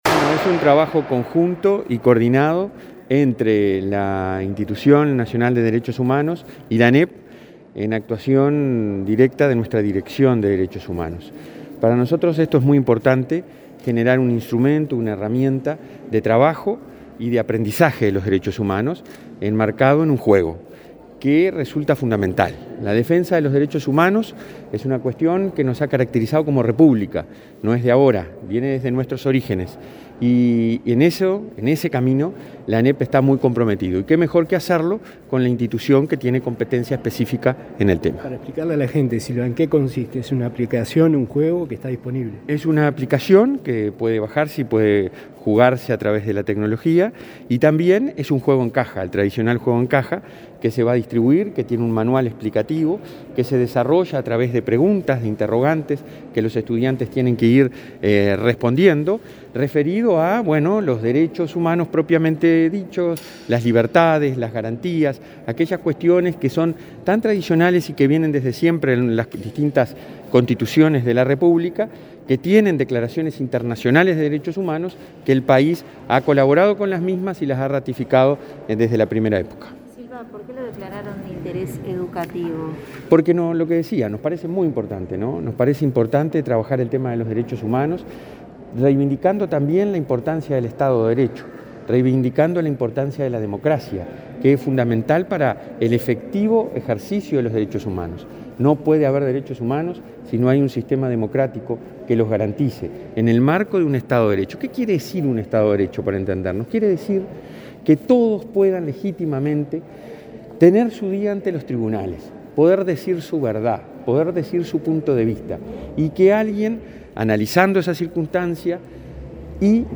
Declaraciones a la prensa del presidente de ANEP